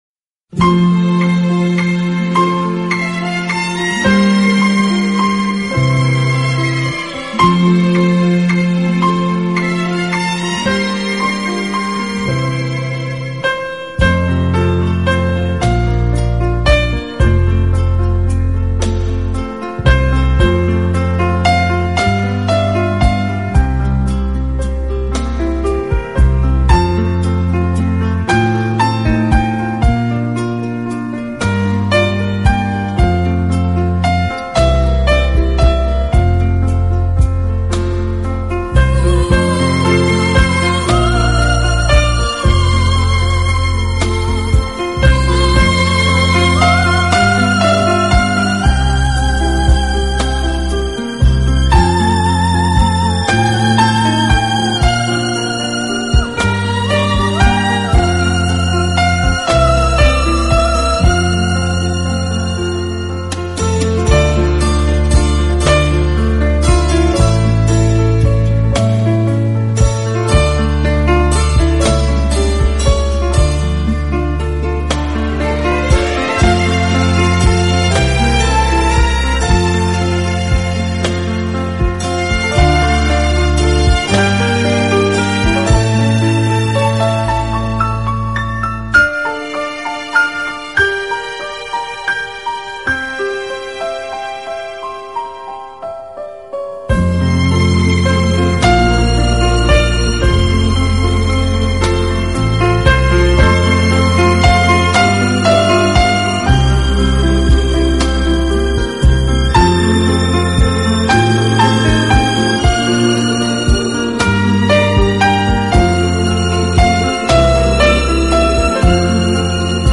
【轻音乐合辑】
轻音乐作品的旋律优美动听、清晰流畅，节奏鲜明轻快，音色丰富多彩，深受